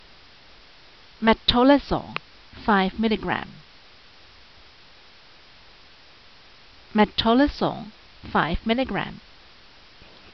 Pronunciation[edit]
Metolazone_5mg.mp3